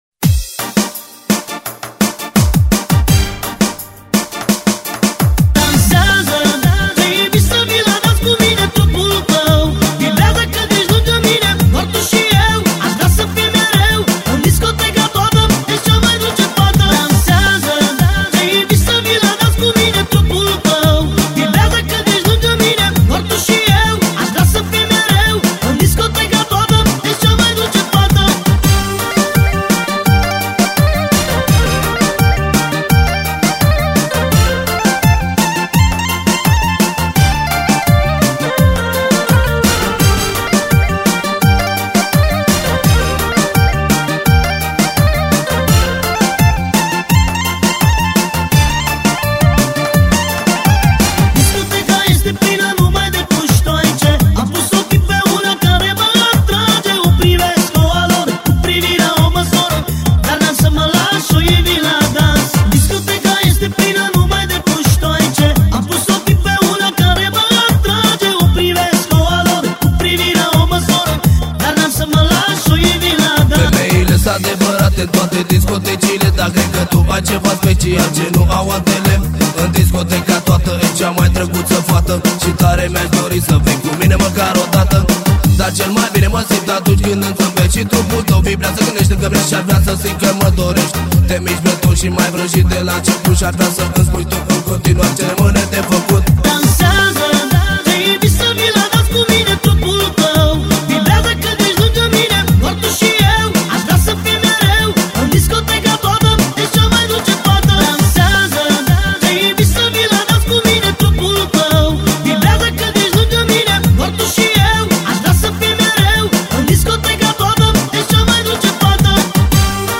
vostochnaya_tanceval_naya.mp3